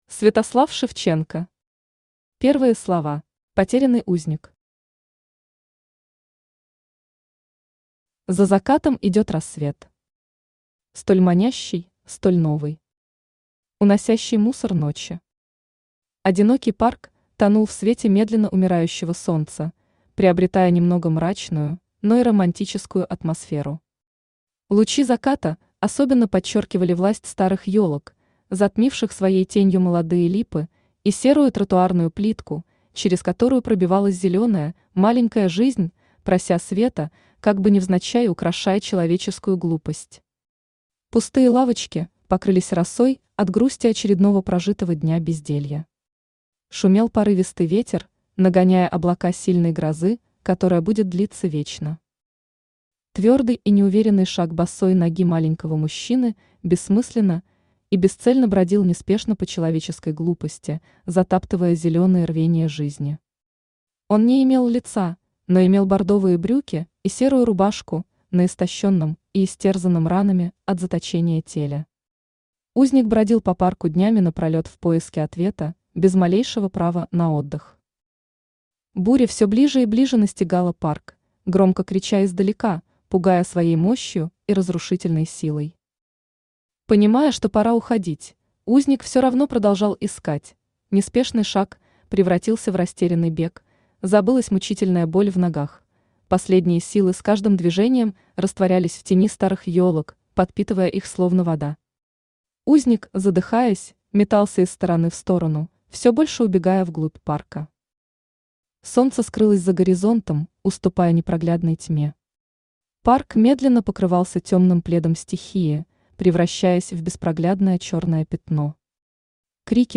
Аудиокнига Первые слова | Библиотека аудиокниг
Aудиокнига Первые слова Автор Святослав Романович Шевченко Читает аудиокнигу Авточтец ЛитРес.